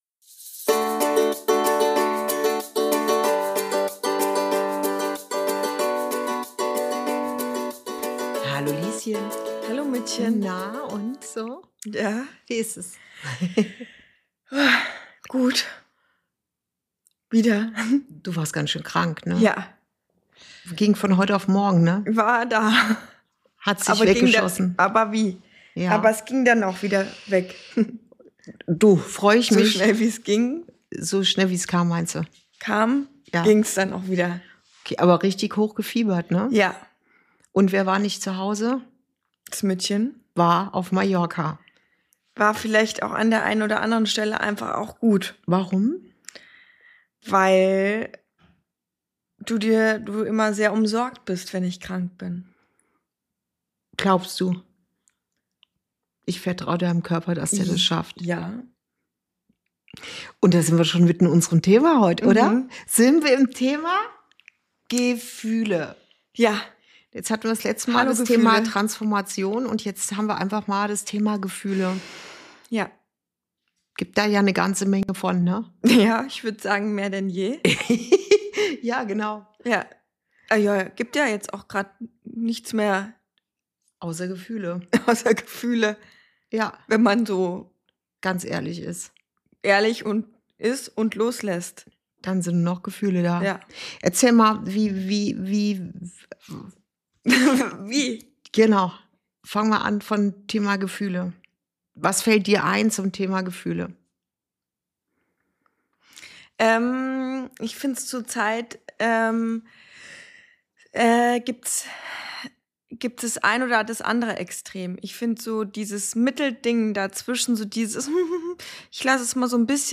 Folge 36: Gefühle in der Transformation ~ Inside Out - Ein Gespräch zwischen Mutter und Tochter Podcast